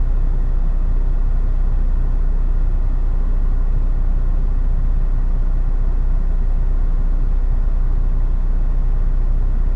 AMBIENCE_SciFi_Hum_01_loop_stereo.wav